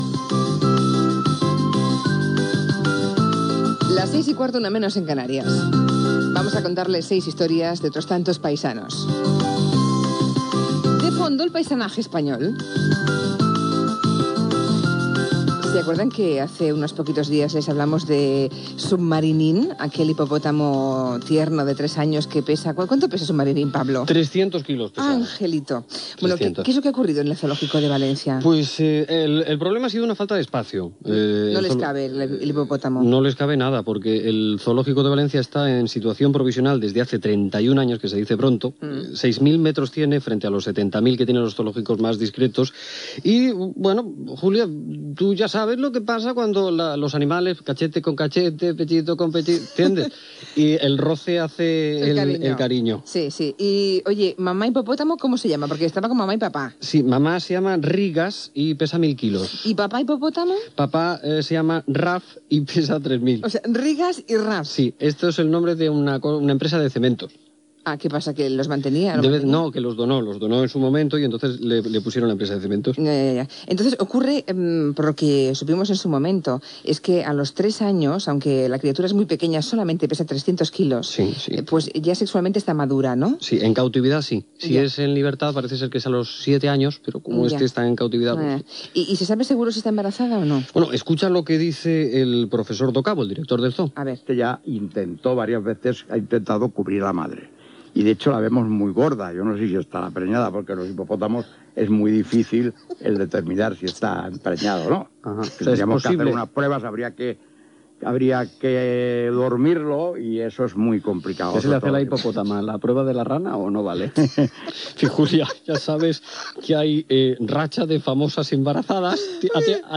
Intervenció del col·laborador Pablo Motos sobre el zoo de València.
Entreteniment